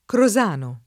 [ kro @# no ]